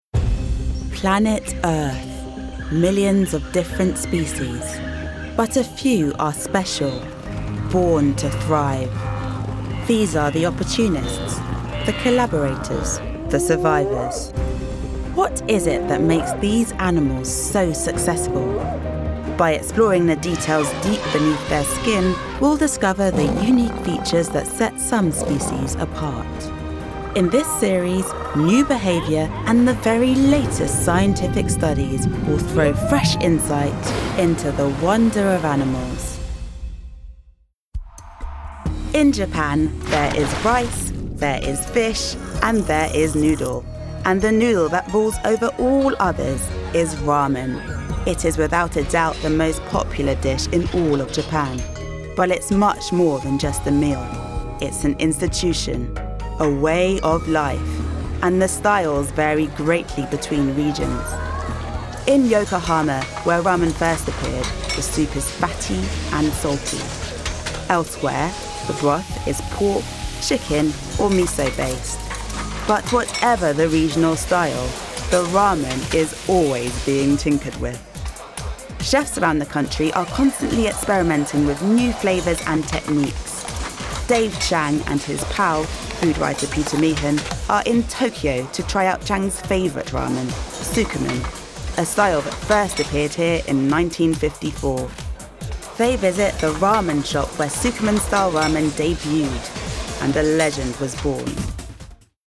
• Native Accent: London, RP
• Home Studio